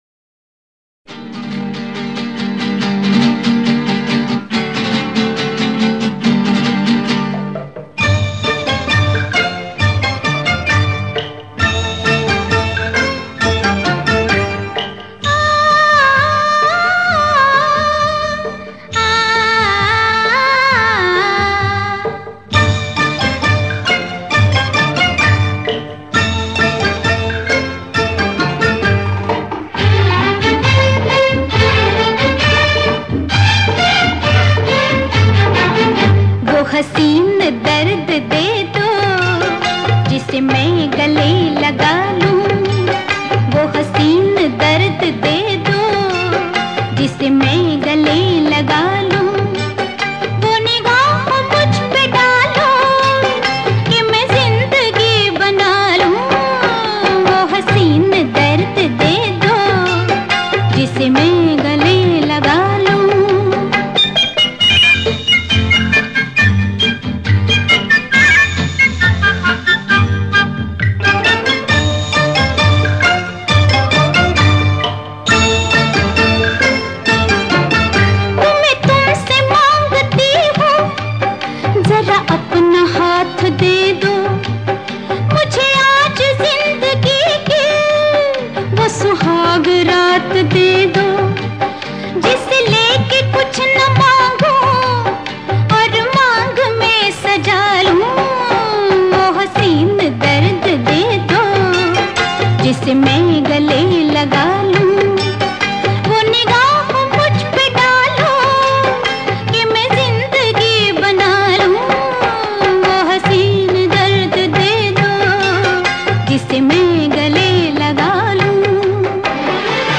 原版唱片
本专辑是宝莱坞电影插曲原版精选辑